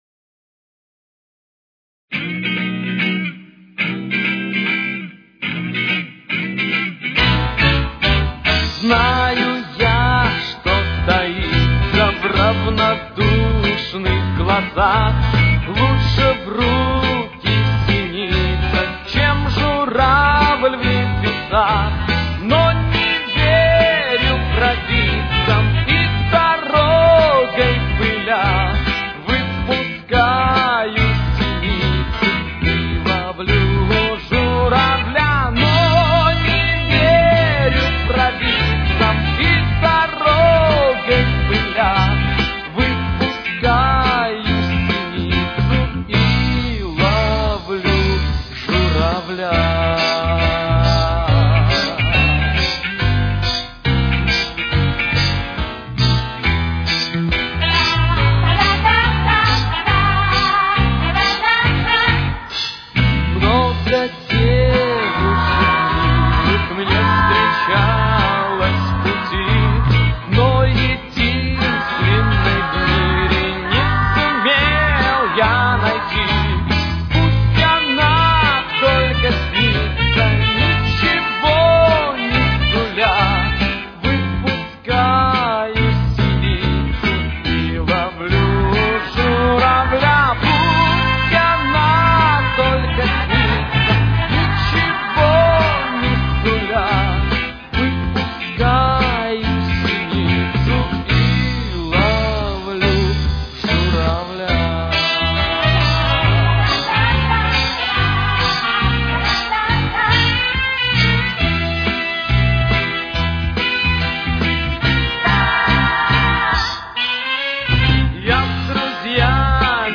с очень низким качеством (16 – 32 кБит/с)
Ми минор. Темп: 71.